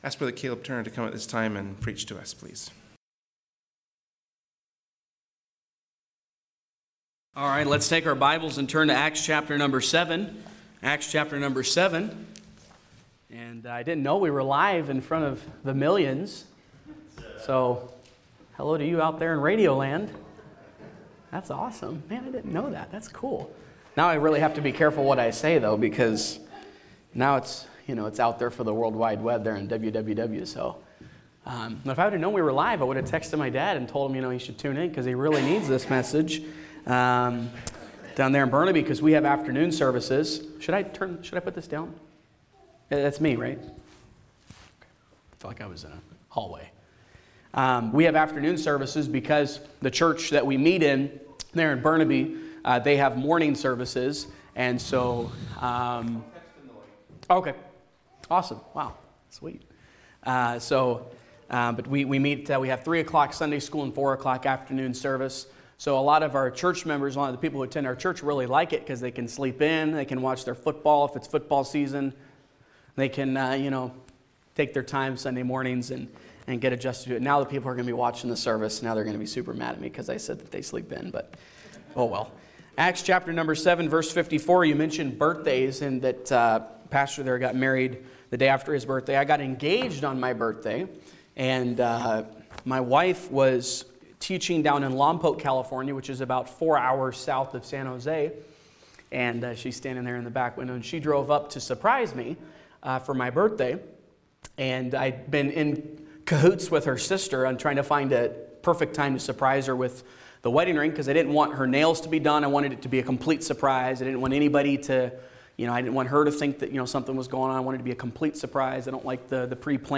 Genre: Preaching.
Service Type: Sunday Morning Worship Service